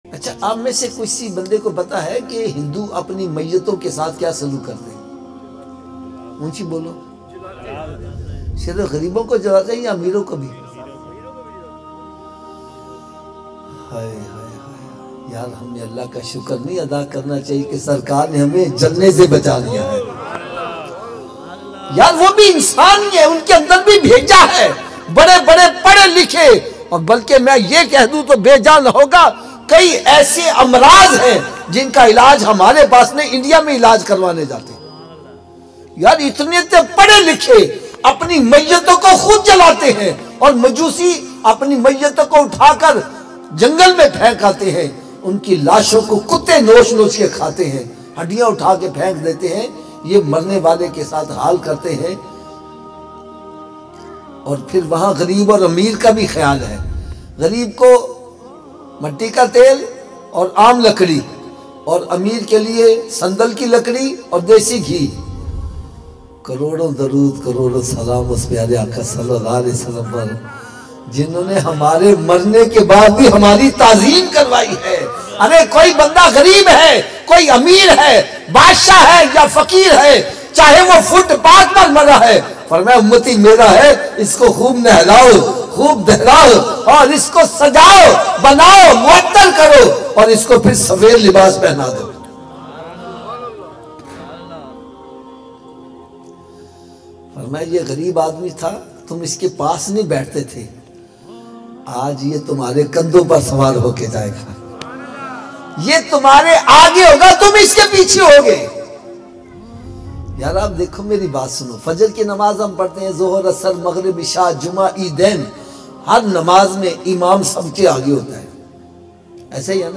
Heart Touching Bayan